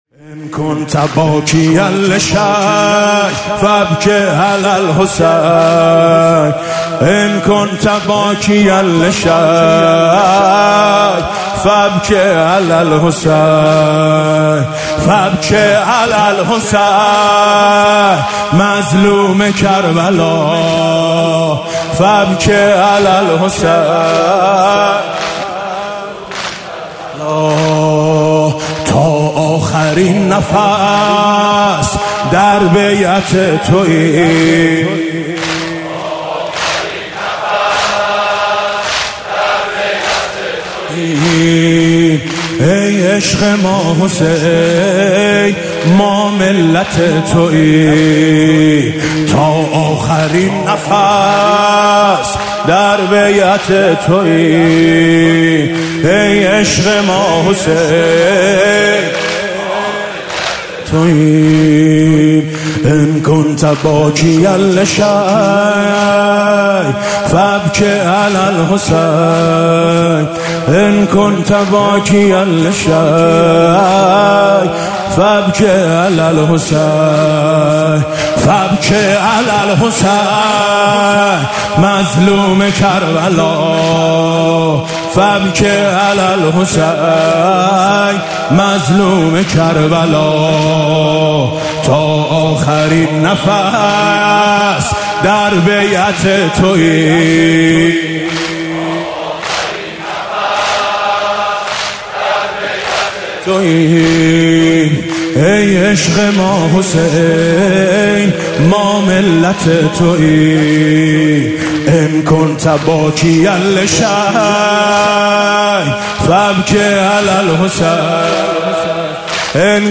مداحی شب دوم محرم 1399 با نوای میثم مطیعی
آخرين خبر/ مداحي شب دوم محرم 1399 با نواي ميثم مطيعي، هيئت ميثاق با شهدا